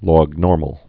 (lôg-nôrməl, lŏg-)